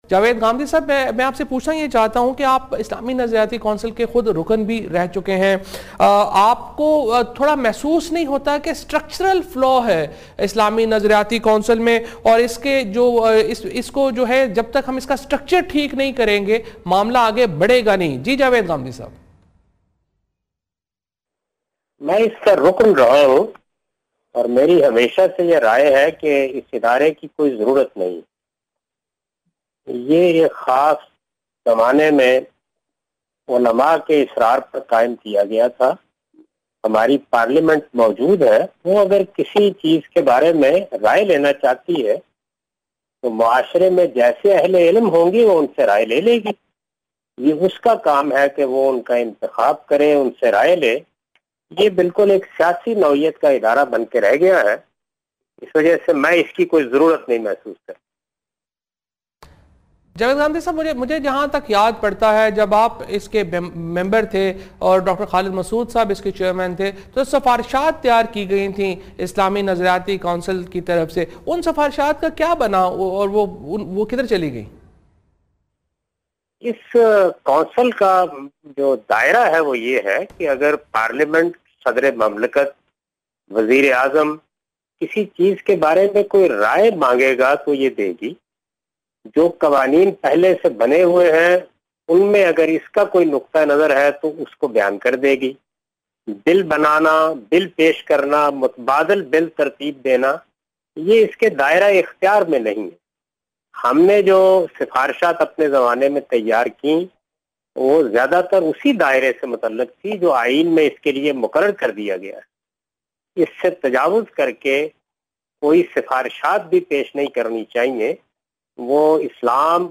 Category: TV Programs / Neo News /
In this program Javed Ahmad Ghamidi discusses "Suggestions of Islamic Ideology Council about Women Status" on Neo News.